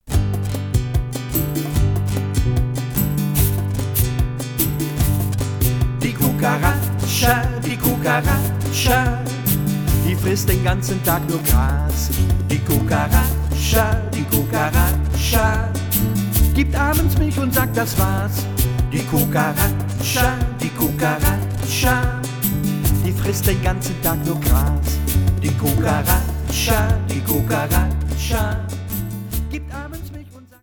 und andere Lieder für den Kindergarten
Frische Rhythmen für die Kleinen!